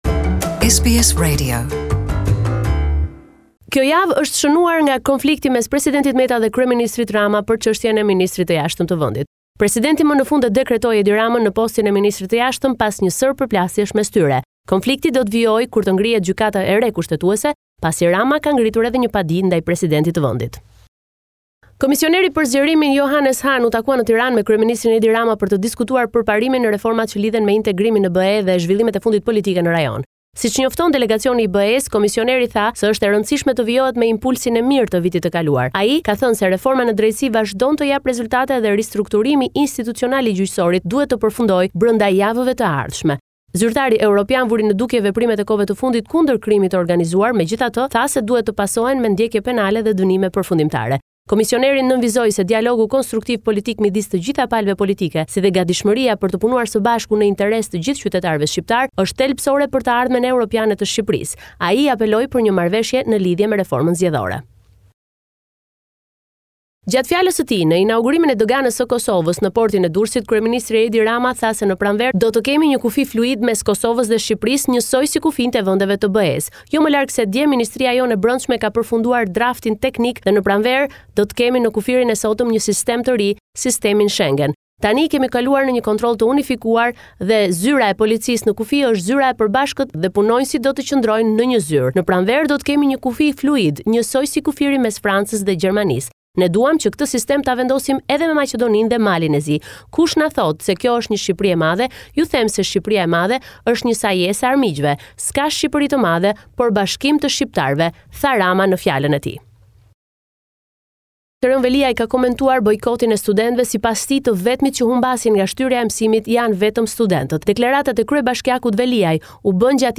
This is a report summarising the latest developments in news and current affairs in Albania.